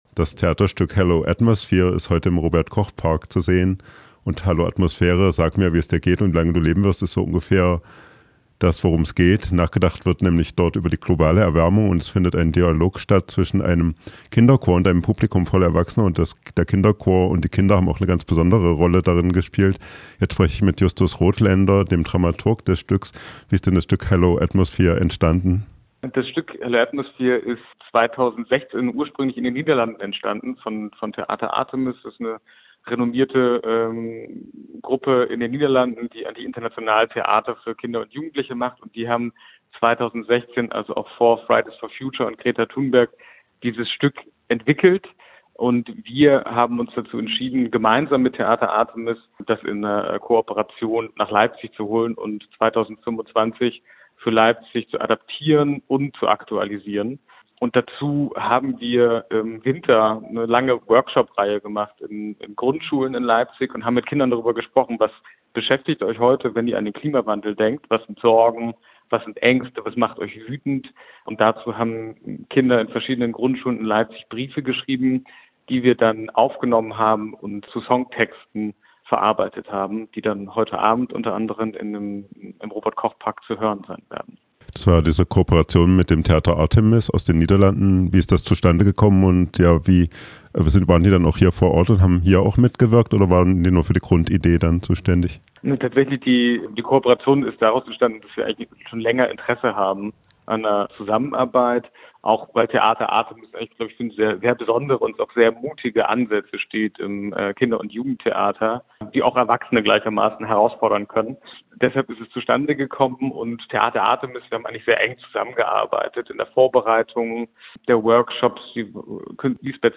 Gespräch zum Theaterstück „»Hello Atmosphere“, das ist am Dienstag 24.6.25 im Robert-Koch-Park in Leipzig zu sehen.